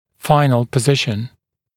[‘faɪnl pə’zɪʃn][‘файнл пэ’зишн]окончательное положение